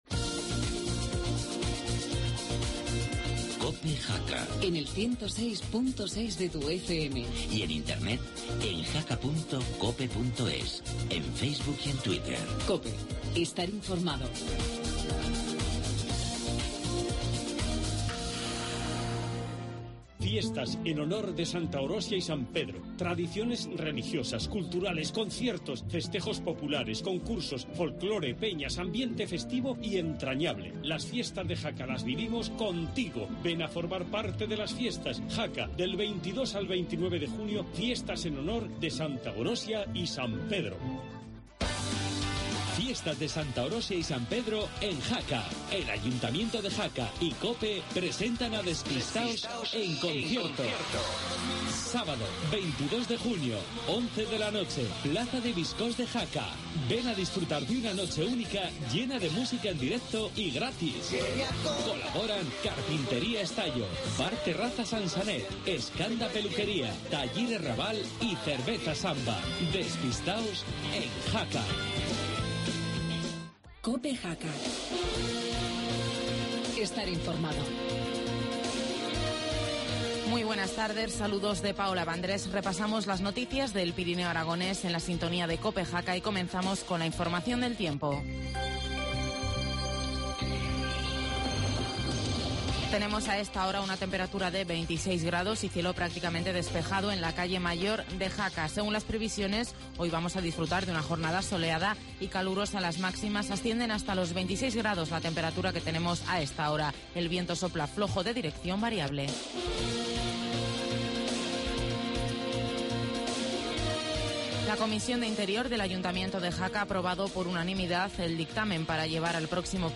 Informativo mediodía, miércoles 12 de junio